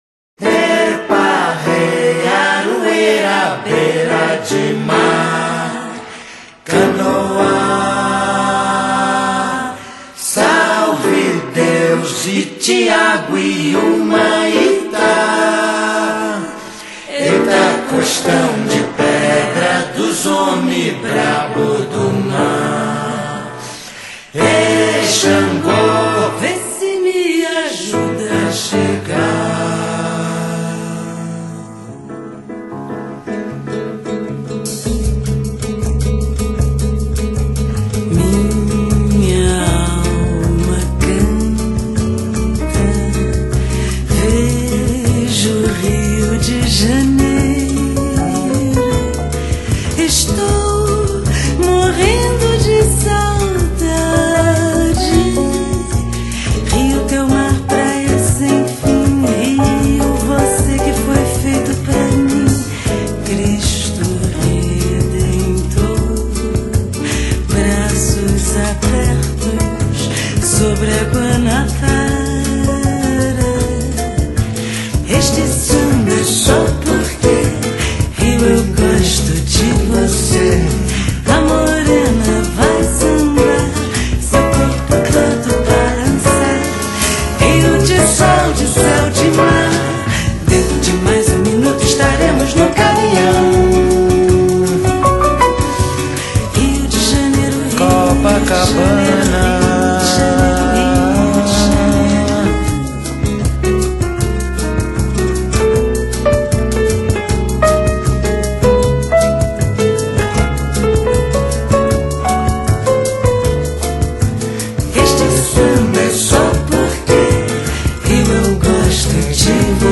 Bossa Nova Para Ouvir: Clik na Musica.